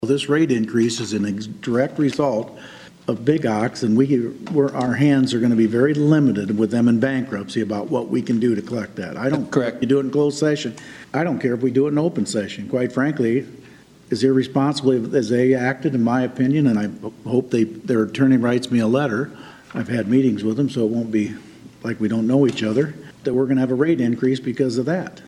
Mayor Bob Scott placed the blame Monday during the city council meeting on the South Sioux City company which owes the city around three million dollars in unpaid fees and is currently not operating: